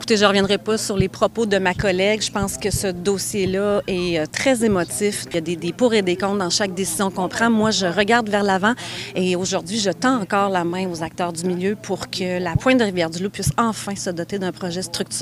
La députée a mentionné qu’elle préfère « regarder vers l’avant », essayant ainsi de passer l’éponge sur ce malaise causé par sa collègue Geneviève Guilbault. Voici un extrait de son commentaire, tiré d’un reportage de TVA Nouvelles.